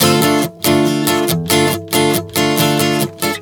Strum 140 Dm 04.wav